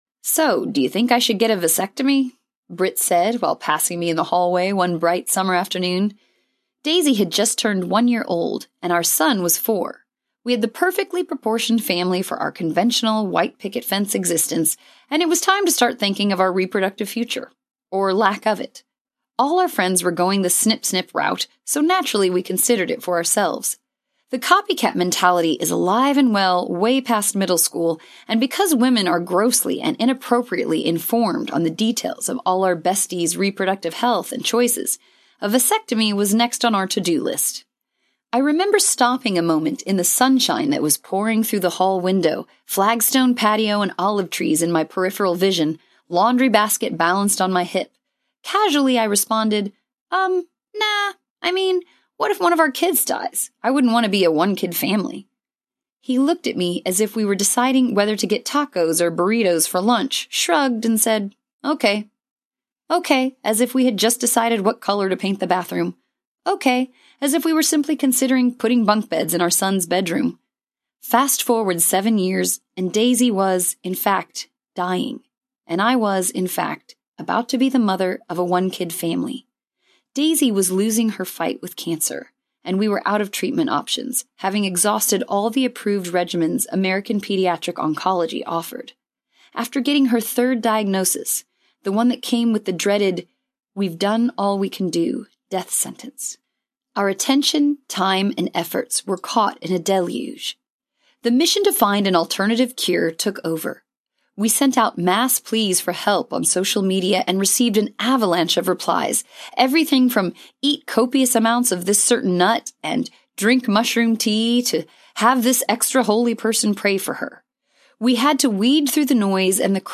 Here, Now Audiobook
Narrator
4.5 Hrs. – Unabridged